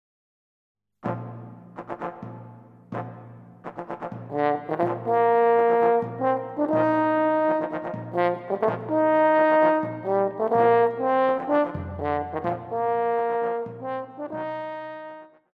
Pop
French Horn
Band
Instrumental
World Music,Fusion
Only backing